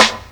GVD_snr (23).wav